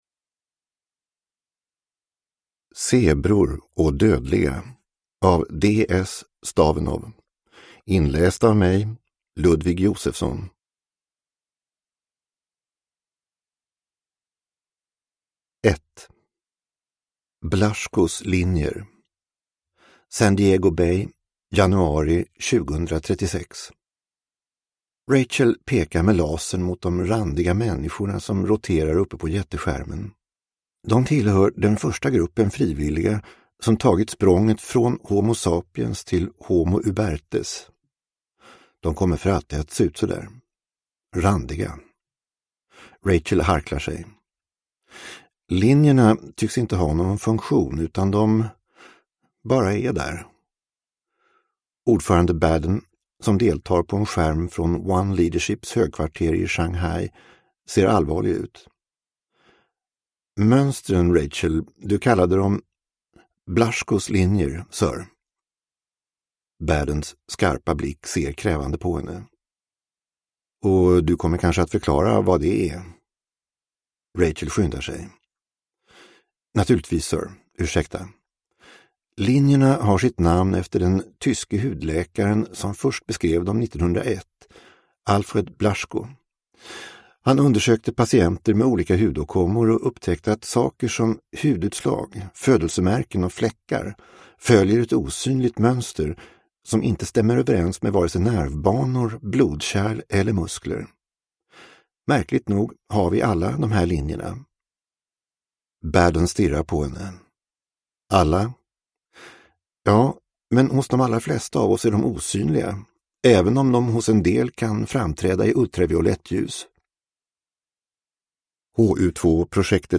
Zebror och dödliga – Ljudbok